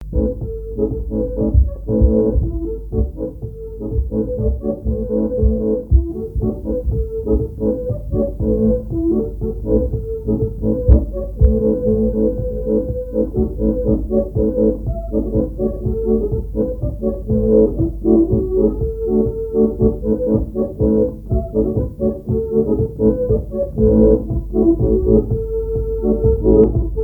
danse : ronde : grand'danse
Répertoire à l'accordéon diatonique
Pièce musicale inédite